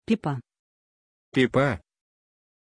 Pronunciation of Pippah
pronunciation-pippah-ru.mp3